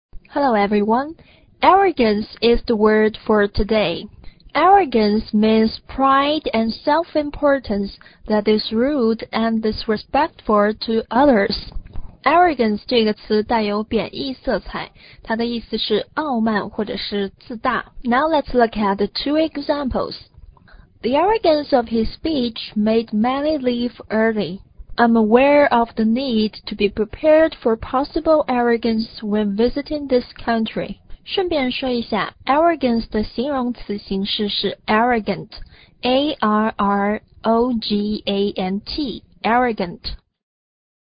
arrogance共3个音节，重音在第一个音节。